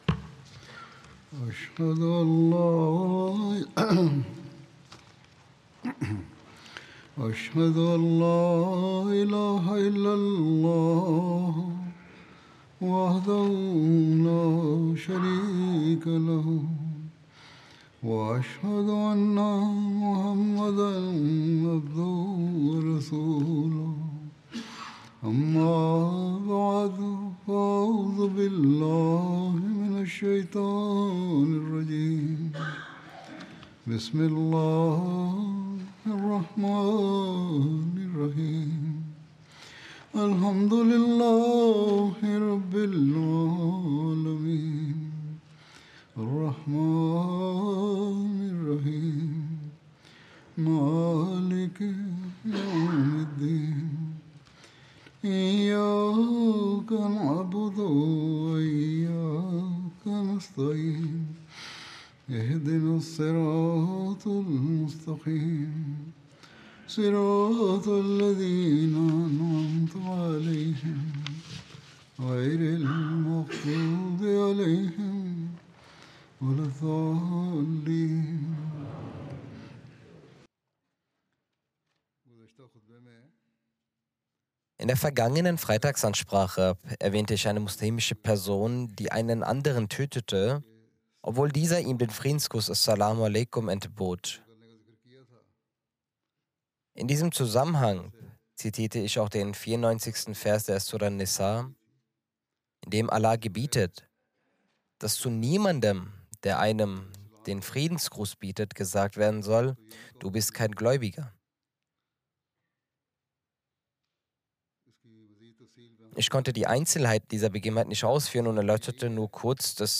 German Friday Sermon by Head of Ahmadiyya Muslim Community
German Translation of Friday Sermon delivered by Khalifatul Masih